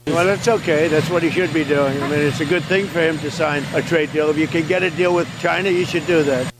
Audio with Canadian Prime Minister Mark Carney, Saskatchewan Premier Scott Moe, and U.S. President Donald Trump.